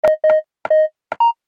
جلوه های صوتی
دانلود صدای کیبورد 19 از ساعد نیوز با لینک مستقیم و کیفیت بالا